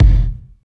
28.04 KICK.wav